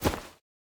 Minecraft Version Minecraft Version snapshot Latest Release | Latest Snapshot snapshot / assets / minecraft / sounds / item / bundle / insert1.ogg Compare With Compare With Latest Release | Latest Snapshot